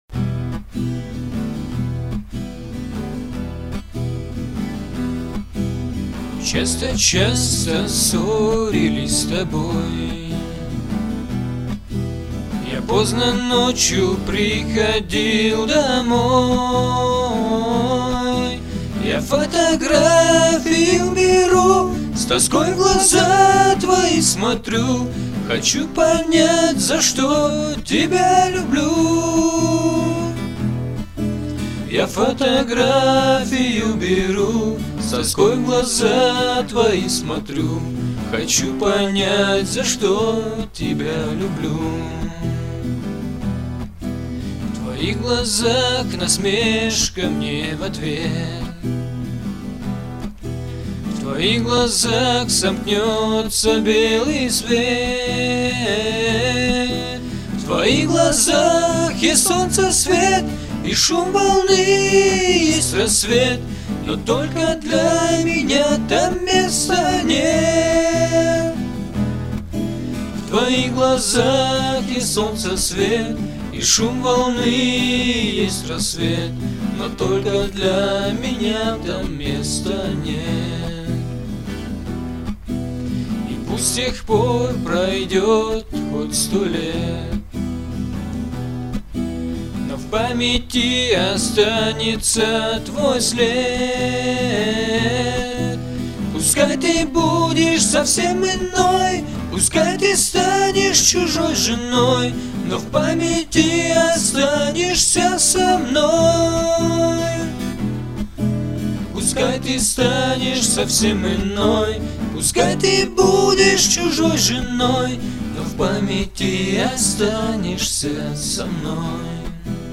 Категория: Песни под гитару